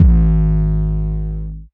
DDK1 808 6.wav